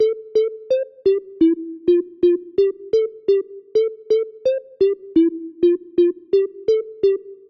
循环的组成我认为是在Progressive House/EDM类型。
Tag: 128 bpm House Loops Synth Loops 1.26 MB wav Key : A